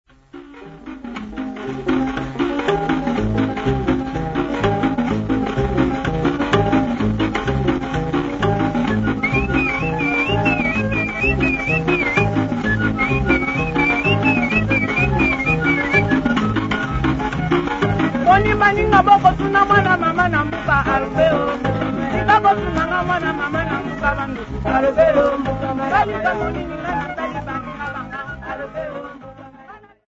Likembe
Hand piano
Singing
Drumming
Radio broadcast
Music